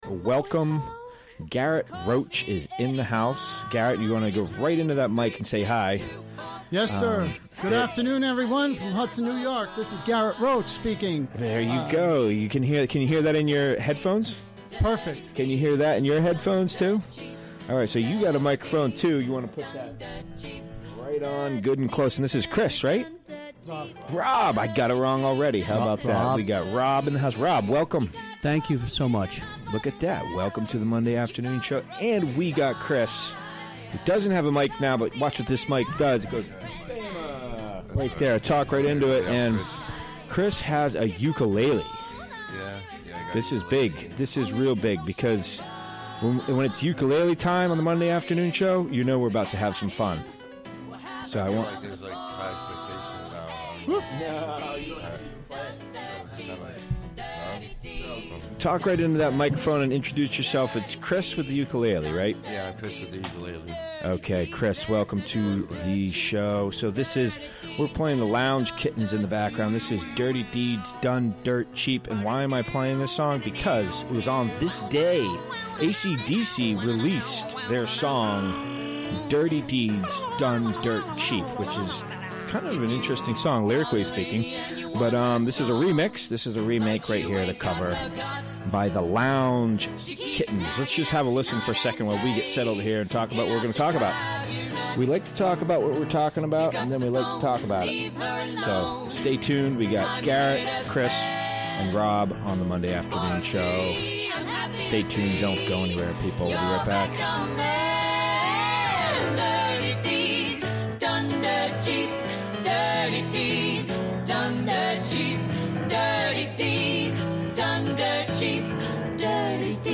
Recorded during the WGXC Afternoon Show Monday, March 27, 2017.